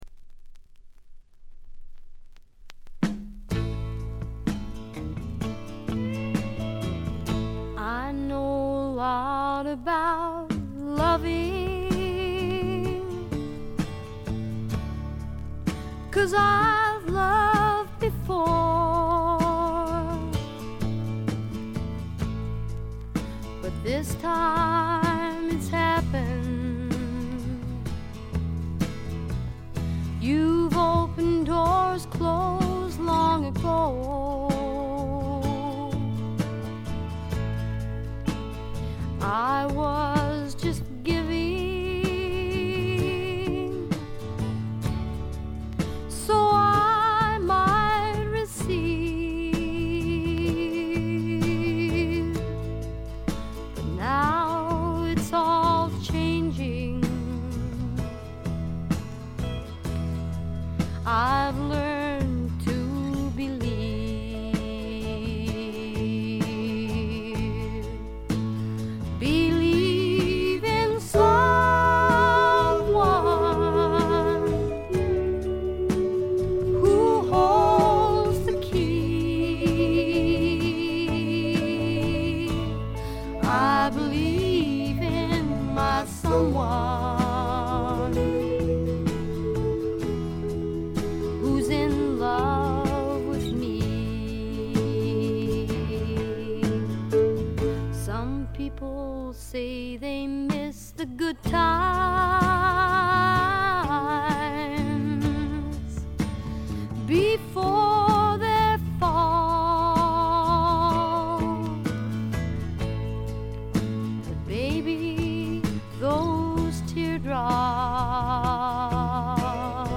ごくわずかなノイズ感のみ。
トリオ編成にゲストが加わる編成ですがブルースを基調にアーシーなサウンドを聴かせてくれます。
試聴曲は現品からの取り込み音源です。